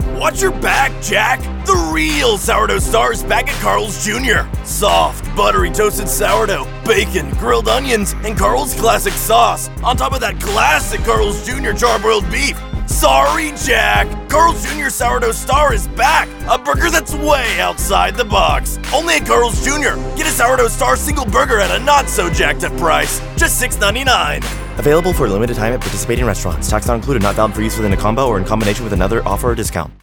Teenager (13-17) | Yng Adult (18-29)